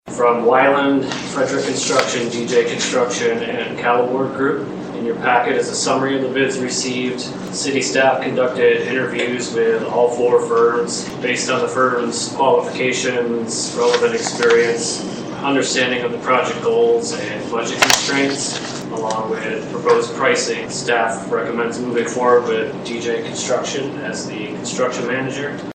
Monday night, Bronson City Manager Brandon Mersman shared with the City Council that four bids were sent in from companies that were interested in doing the project.